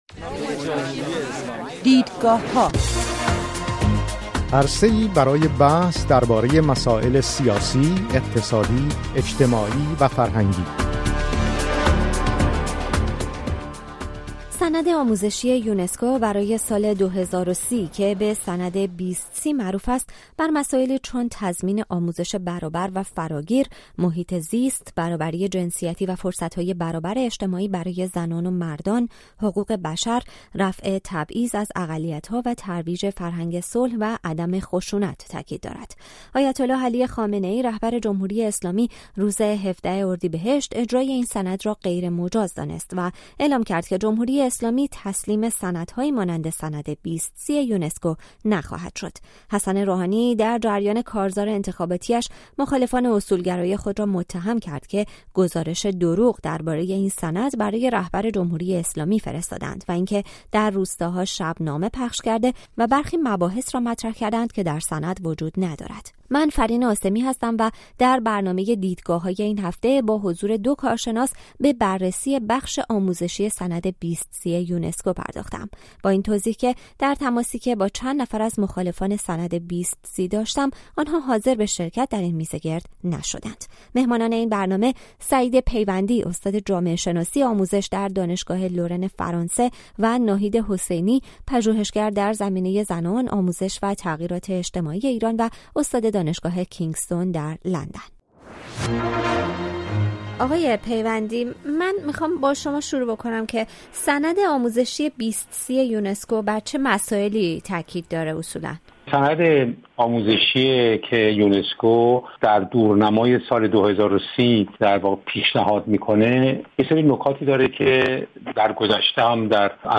این هفته برنامه دیدگاه‌ها با حضور دو کارشناس،
با این توضیح که از مخالفان این سند، کسی حاضر به شرکت در این میزگرد نشد.